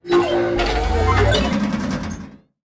Npc Robot Powerup Sound Effect
npc-robot-powerup.mp3